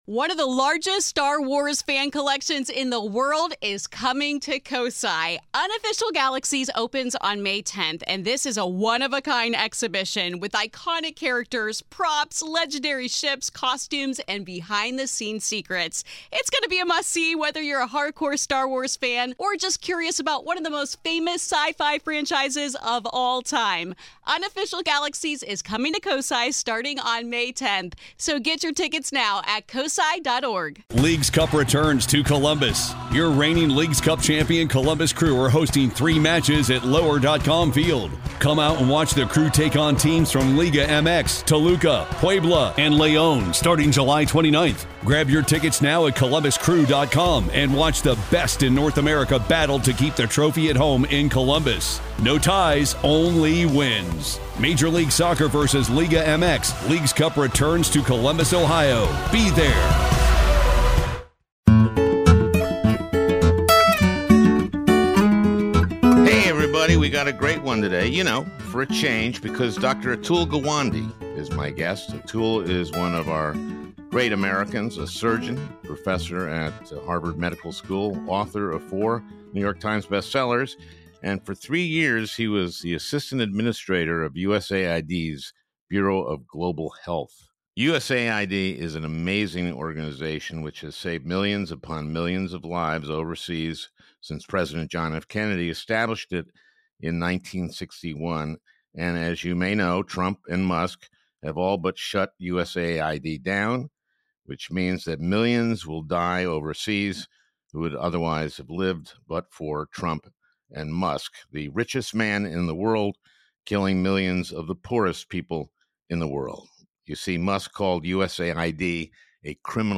We're joined by American surgeon, author, and public health advocate Atul Gawande to discuss just how devastating these actions are.